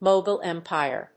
アクセントMógul Émpire